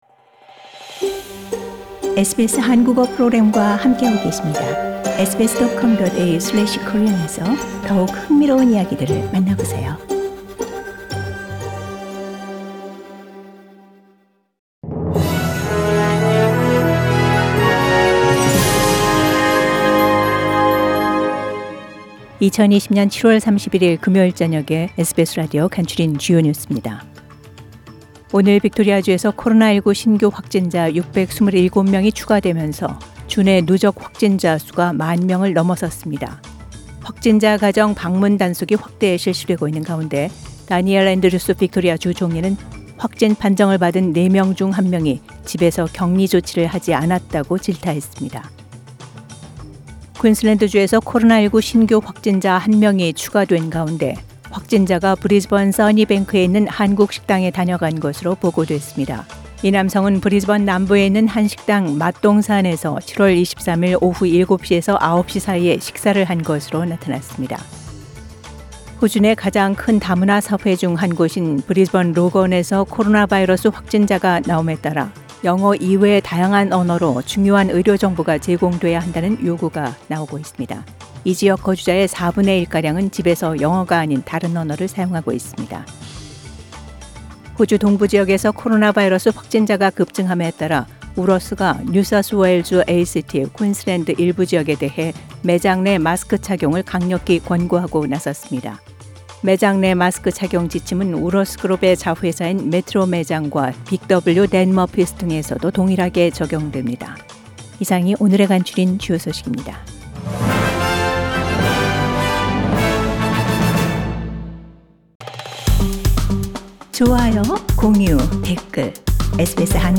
SBS 한국어 뉴스 간추린 주요 소식 – 7월 31일 금요일